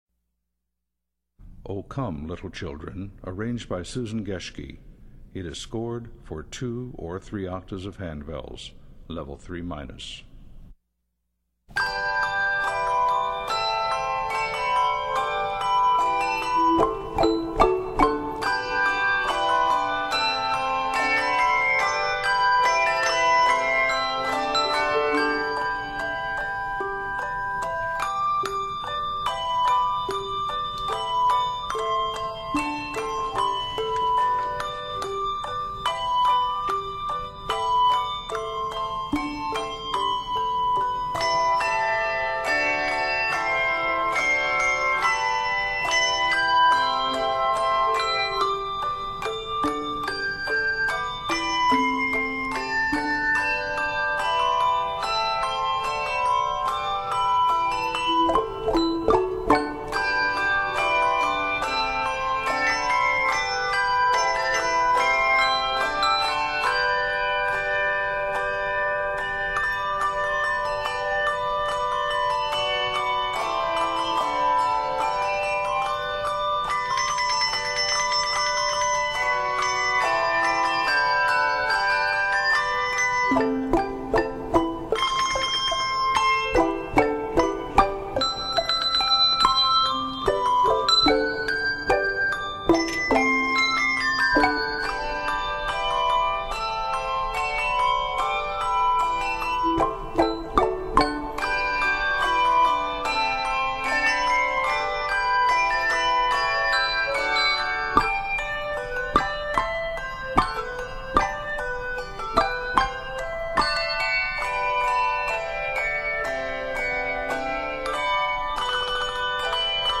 knows how joyous and bouncy they become